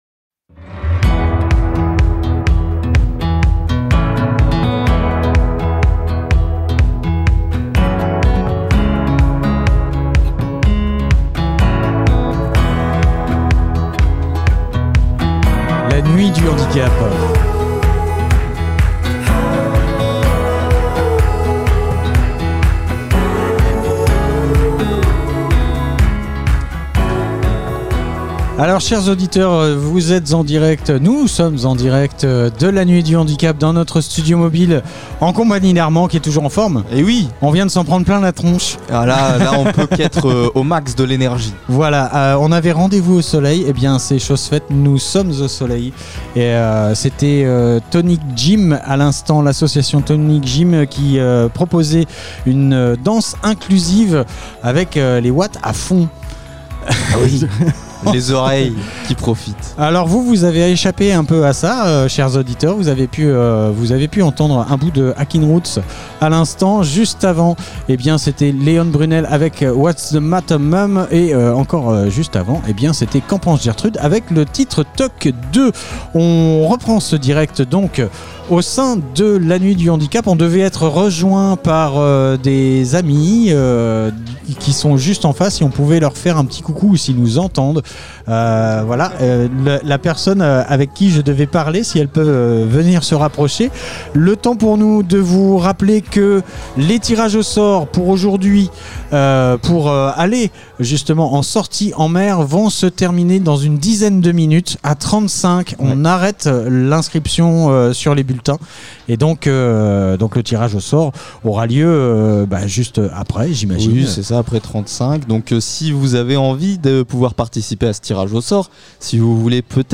La nuit du handicap événement Interview handicap fécamp ville de fécamp nuit ville Nuit du Handicap